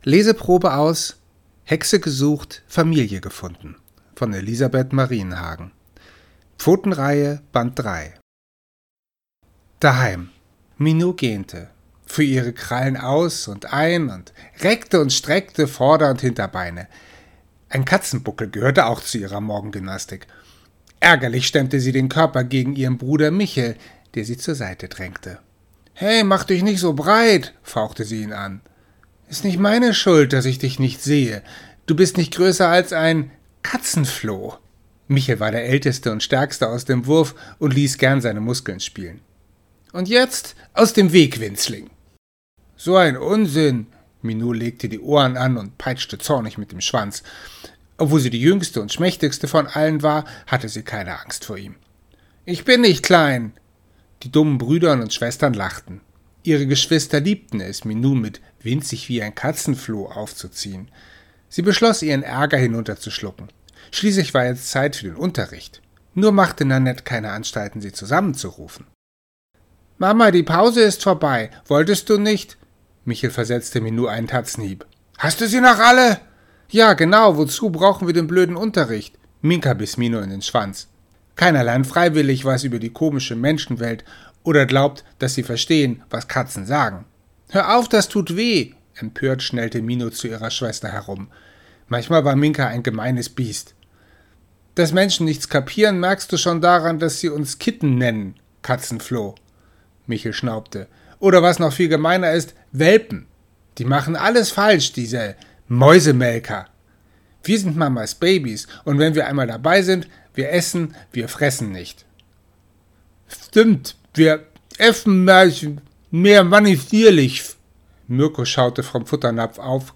Leseprobe als Hörprobe